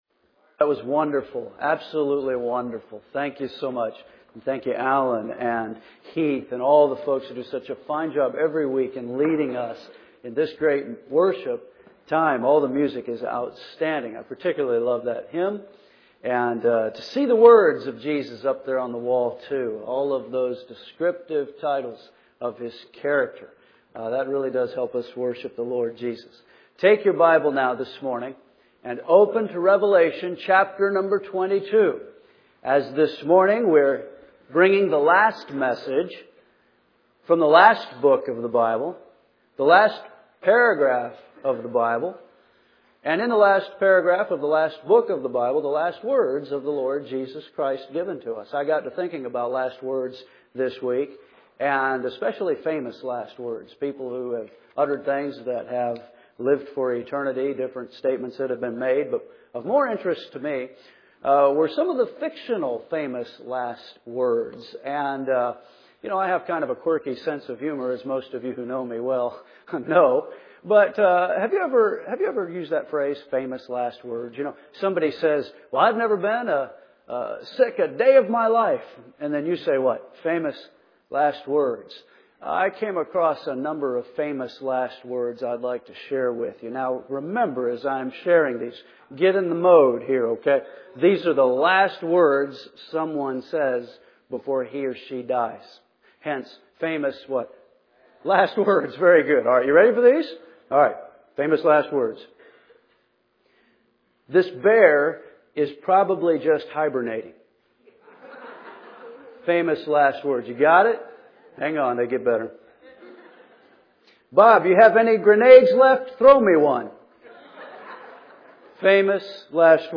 First Baptist Church, Henderson KY